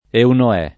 vai all'elenco alfabetico delle voci ingrandisci il carattere 100% rimpicciolisci il carattere stampa invia tramite posta elettronica codividi su Facebook Eunoè [ euno $+ ] top. m. — uno dei fiumi del Purgatorio dantesco